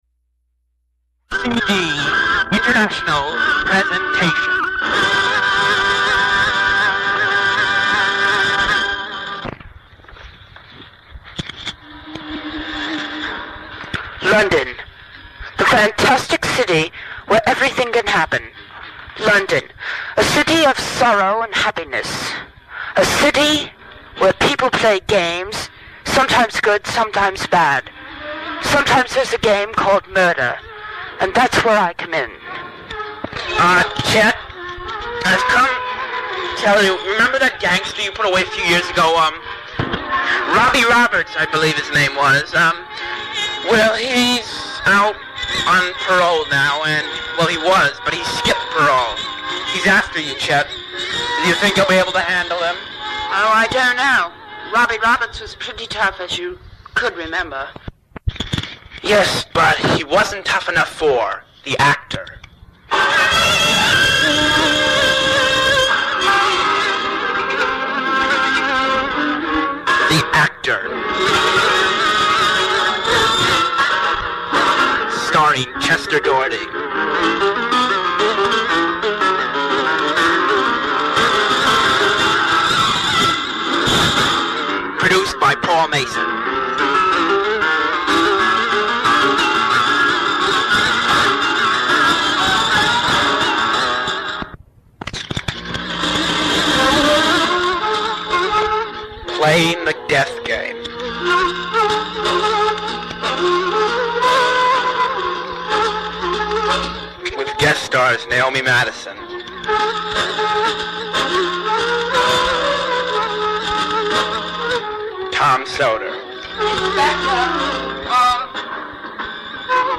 in his best English accent, extolling the virtues of a city (London) or country (Mexico) he is visiting